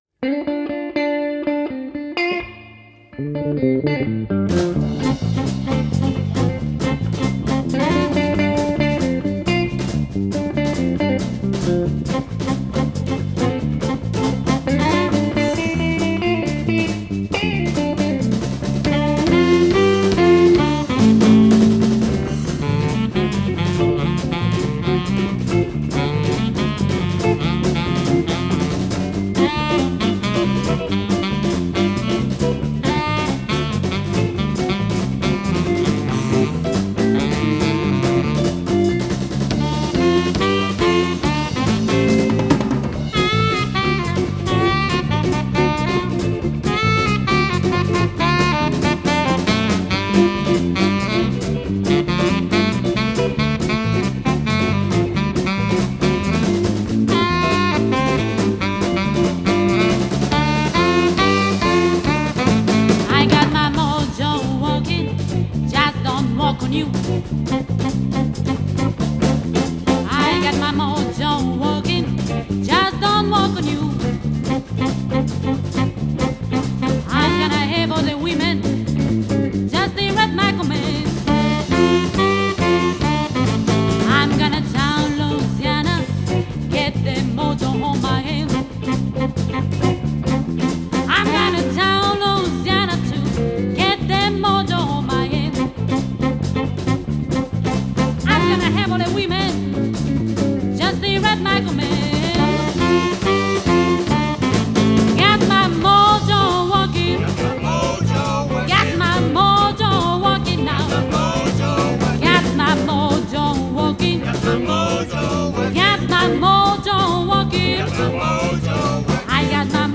registrate al volo